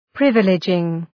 privileging.mp3